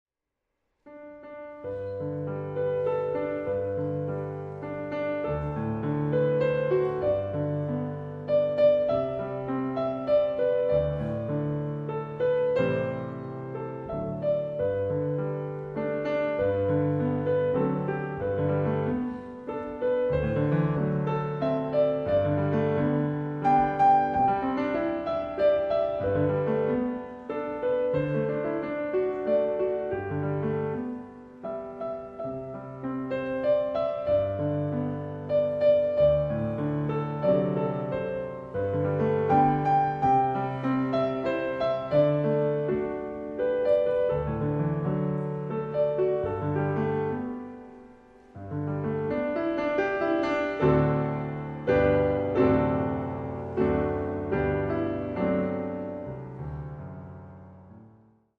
Solo Music, Soul jazz, Contemporary Christian